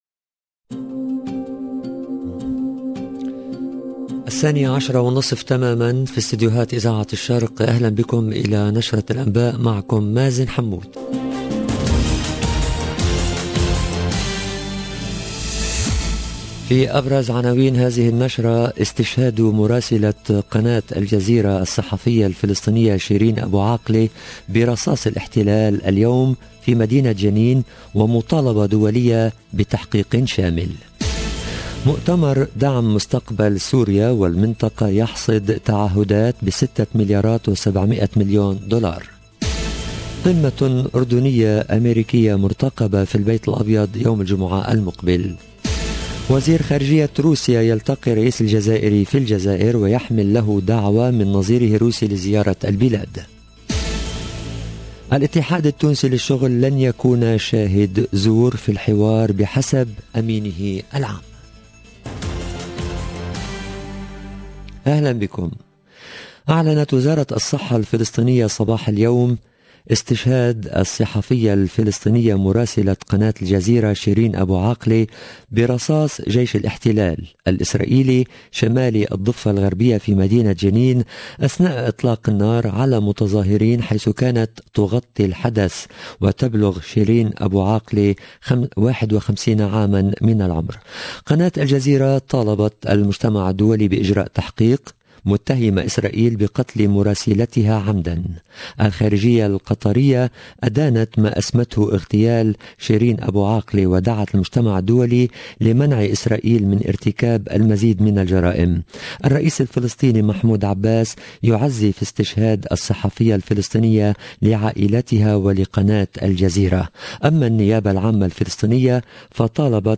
LE JOURNAL DE 12H30 EN LANGUE ARABE DU 11/5/2022